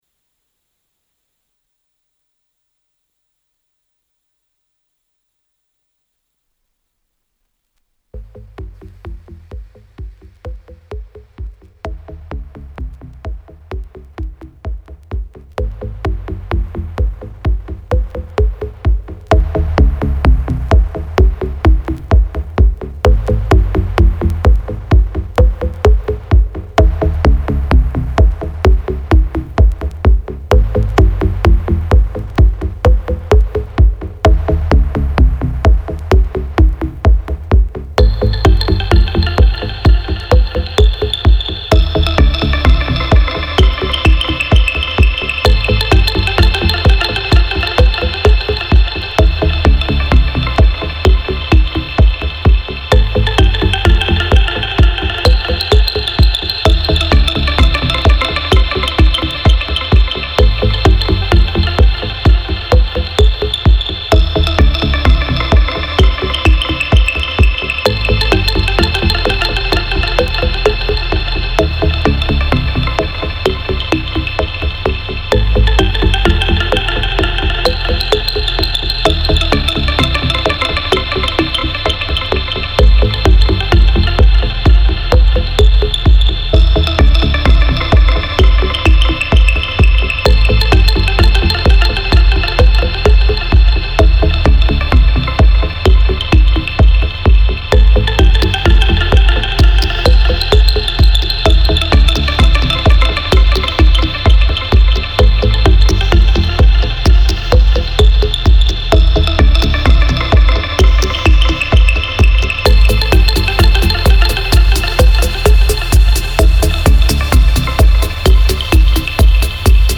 FILE UNDER: Melodic Techno, Minima House, Minimal Techno
mellow tech-house sounds